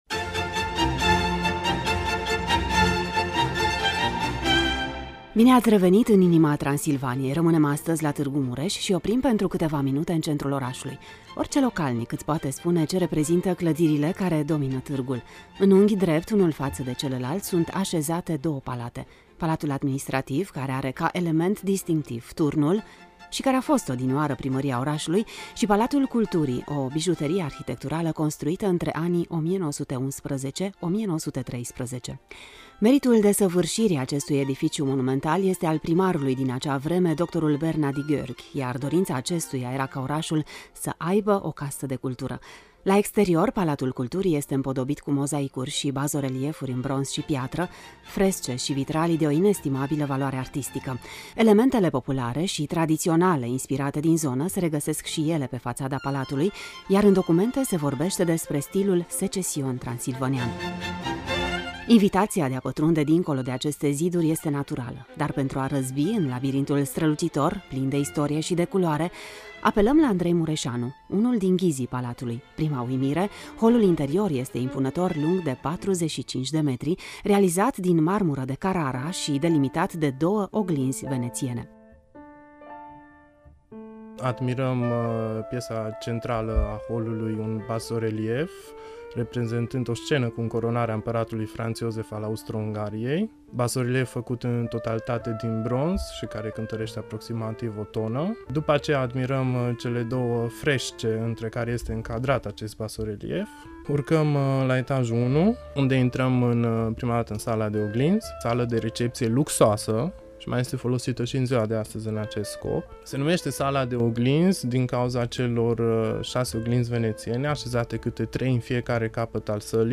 Dacă vă doriţi să descoperiţi, pe îndelete, labirintul strălucitor plin de istorie şi de culoare al Palatului Culturii, ascultaţi reportajul de mai jos.